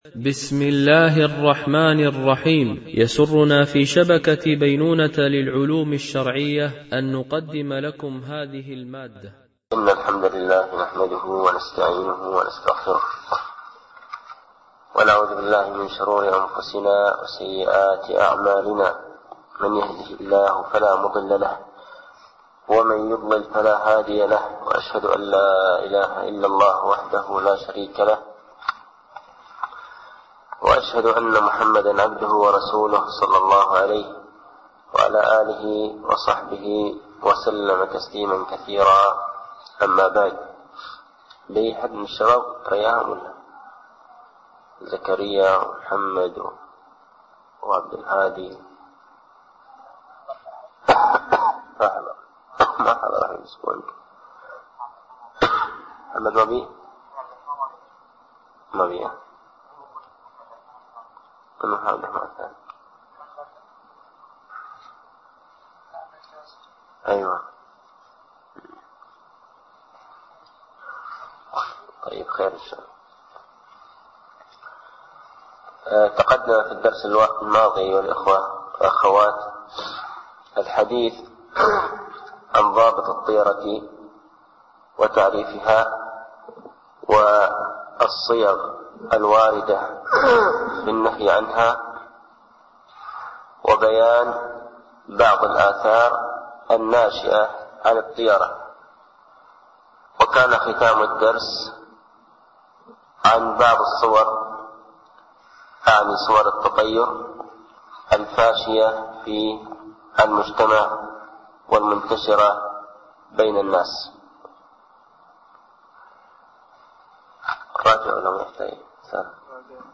) الألبوم: شبكة بينونة للعلوم الشرعية التتبع: 109 المدة: 46:16 دقائق (10.63 م.بايت) التنسيق: MP3 Mono 22kHz 32Kbps (CBR)